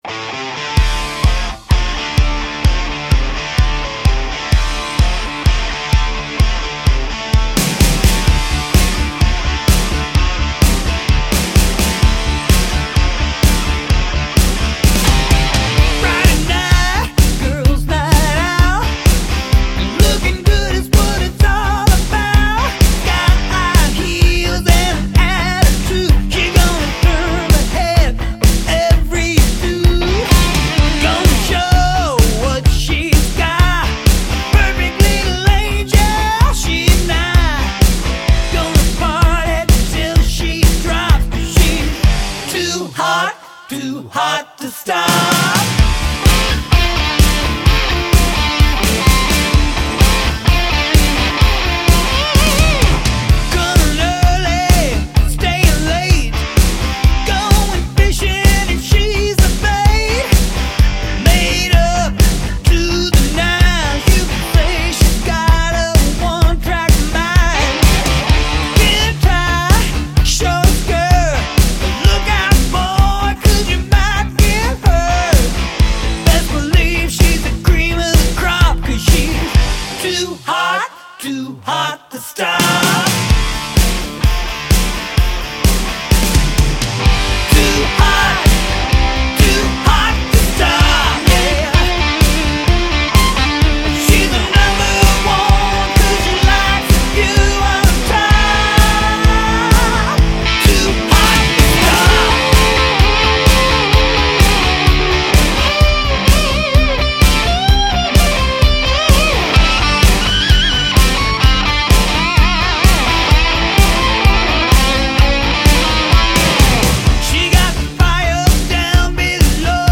Жанры:хард-рок, глэм-метал